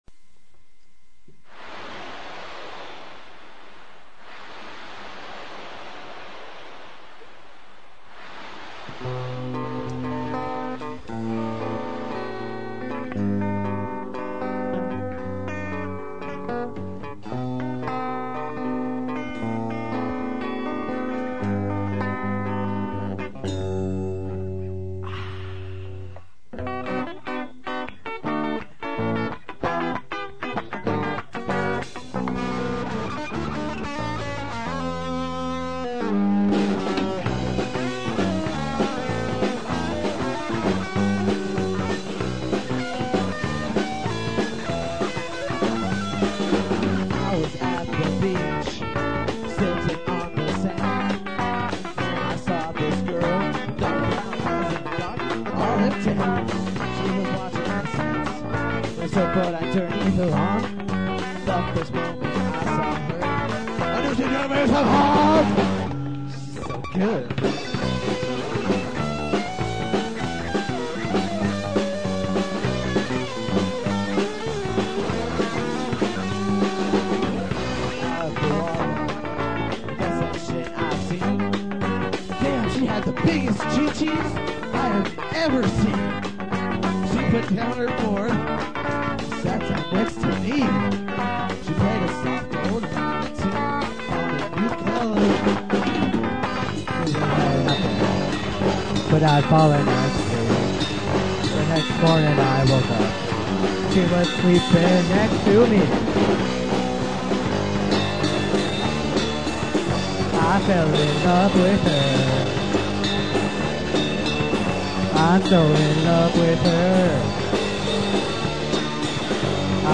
Guitar
Drums/Vocals
Bass/Vocals
Keyboards